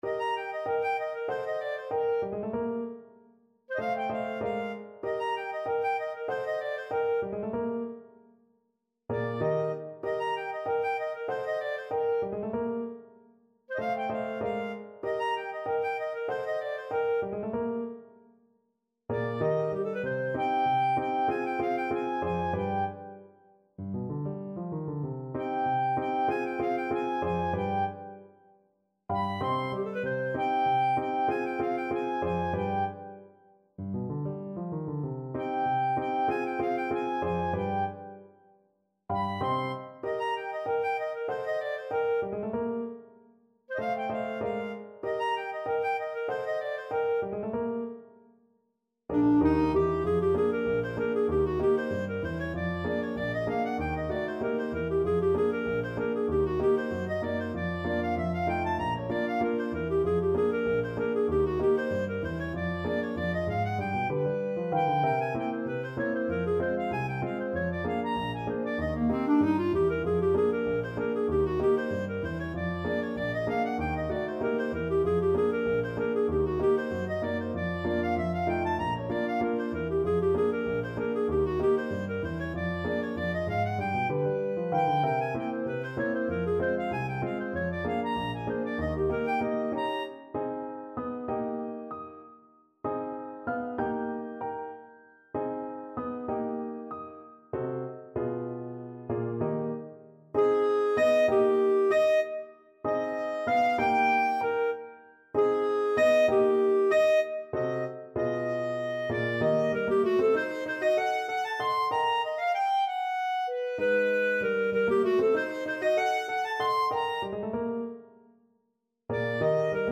Jazz Scott Joplin Stoptime Rag Clarinet version
Clarinet
2/4 (View more 2/4 Music)
Eb major (Sounding Pitch) F major (Clarinet in Bb) (View more Eb major Music for Clarinet )
Jazz (View more Jazz Clarinet Music)